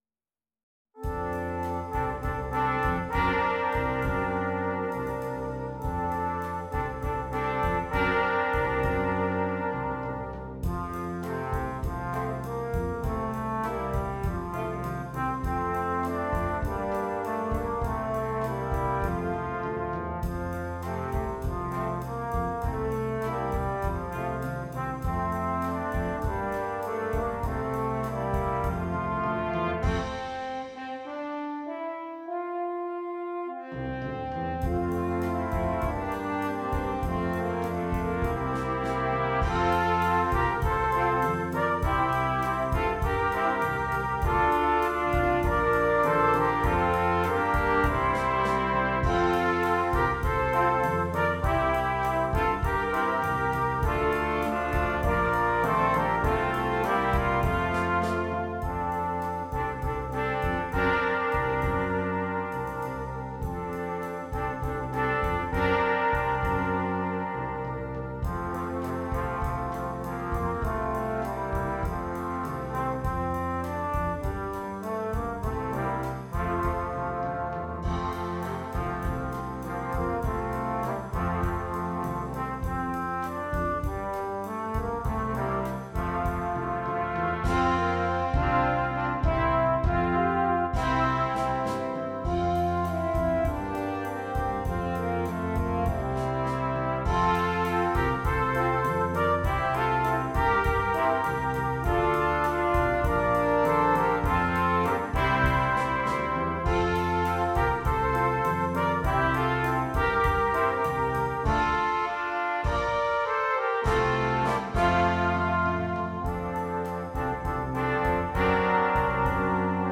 Brass Quintet (optional Drum Set)
Traditional